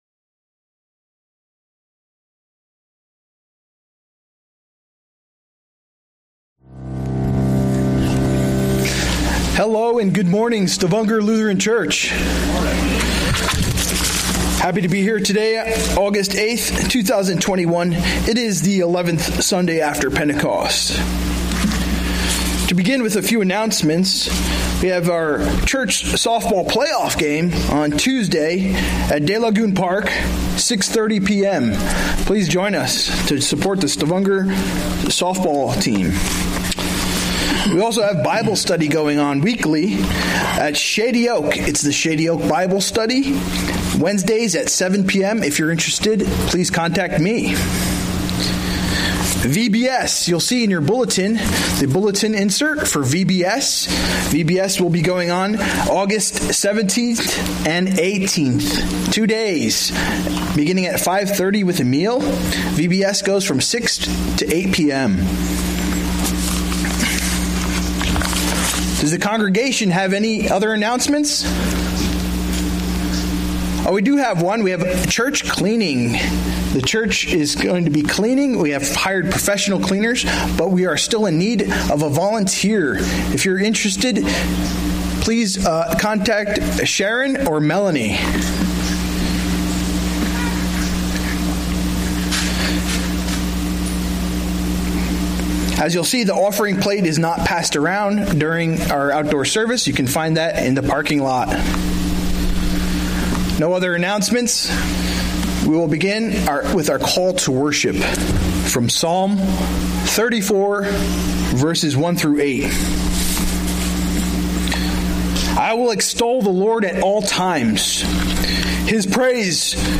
A message from the series "Sunday Worship." Enough - 1 Kings 19:1-8